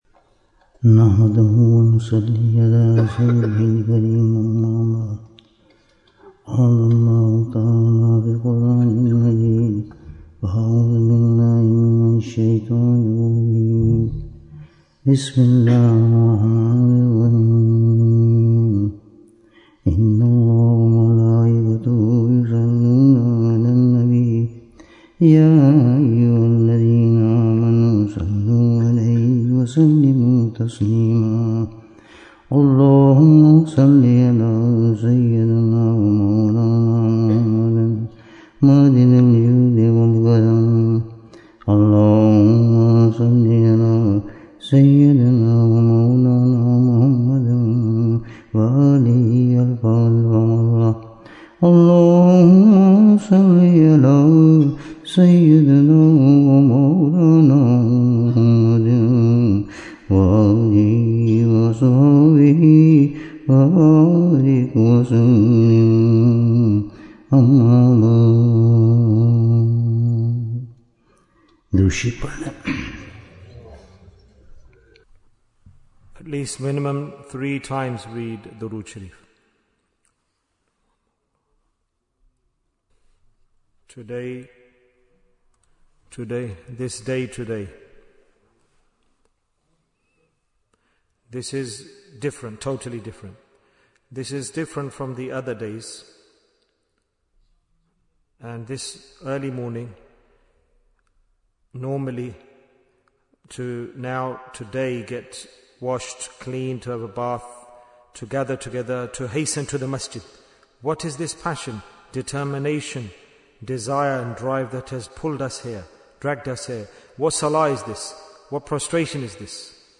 Bayan on Eid-ul-Fitr Bayan, 50 minutes30th March, 2025